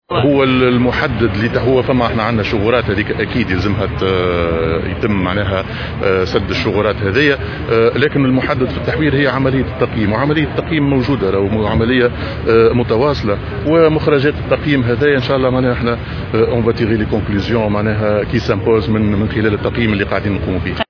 وأوضح المشيشي، لدى إعطائه اليوم شارة انطلاق حملة نظافة بالمؤسسات التربوية، اليوم الأربعاء، أنه سيتم اعتماد مخرجات عملية التقييم لتحديد الوزراء الذين سيقع تعيينهم على رأس الوزارات الشاغرة.